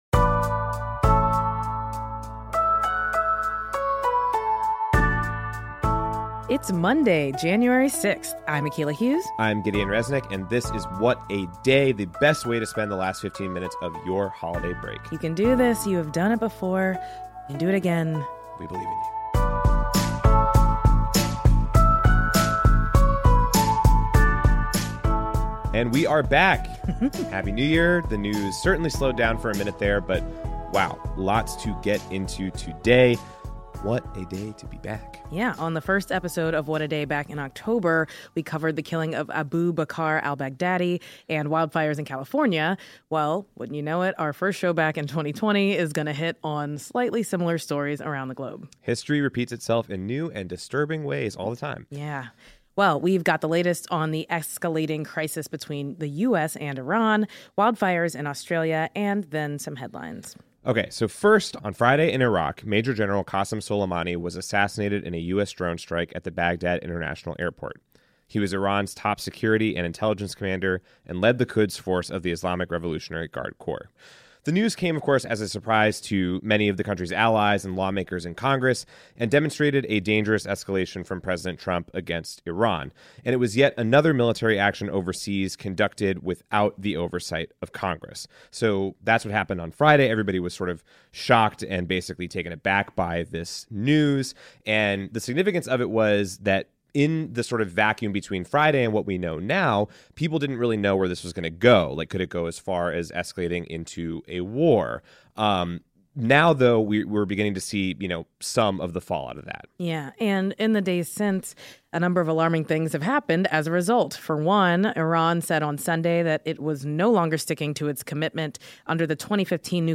The US assassinated Iranian Major General Qassim Suleimani via drone strike last Friday. We discuss the repercussions and talk with California congressman Ro Khanna about the legislation he’s introducing to block funds being used for military actions without congressional authorization.